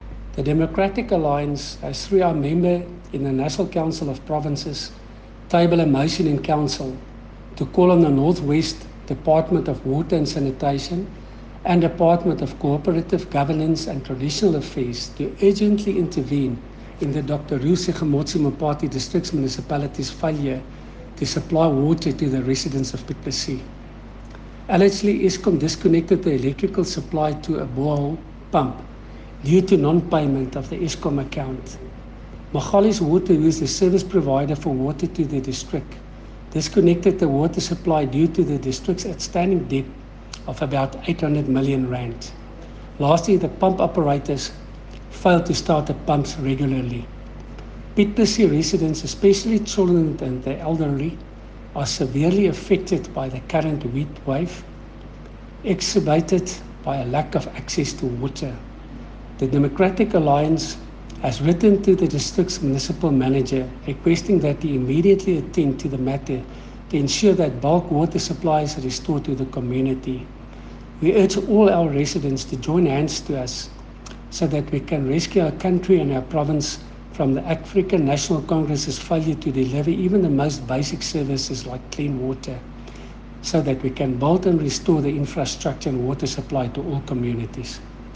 Engels en Afrikaans deur rdl. Coenrad Herbst.